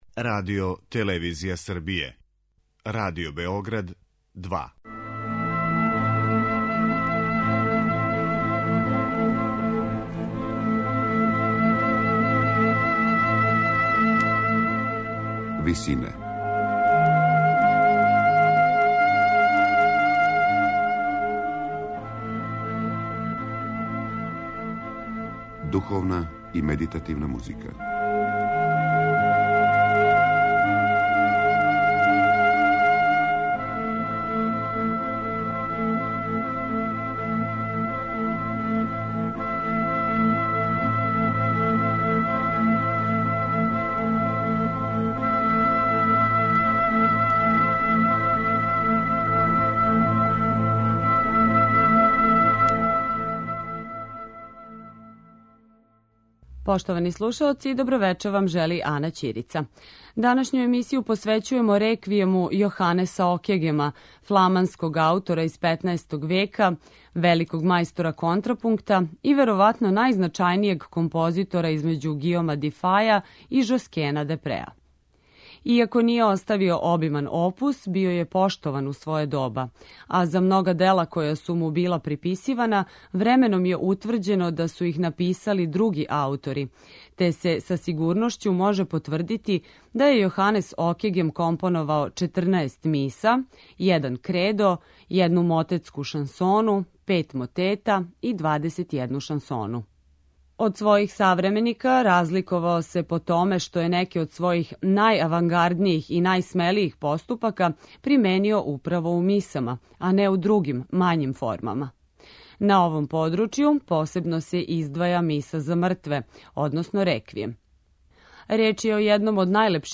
у ВИСИНАМА представљамо медитативне и духовне композиције аутора свих конфесија и епоха.
Данашњу емисију посвећујемо Миси за мртве Јоханеса Окегема, једном од најлепших примера грегоријанског певања и најстаријем примеру полифоне обраде реквијемске мисе.